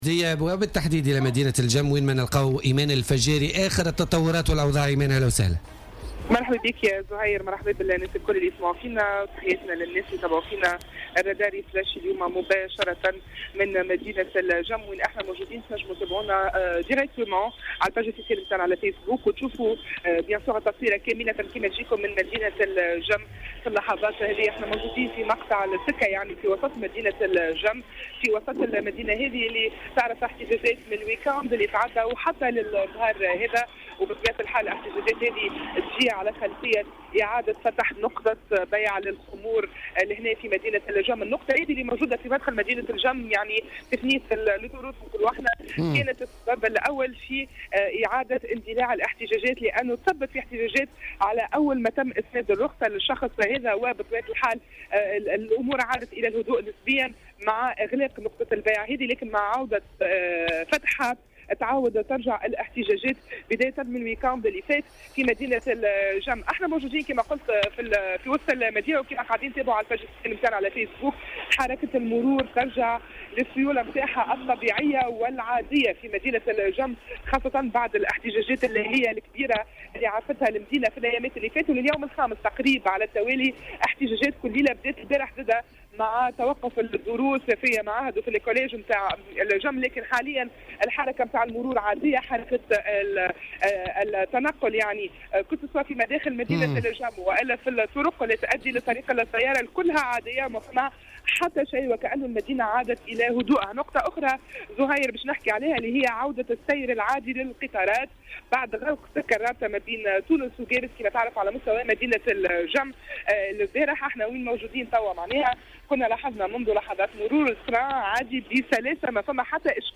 Leila Boussalah, député du mouvement Ennahdha à l'assemblée des représentants du peuple, était l'invité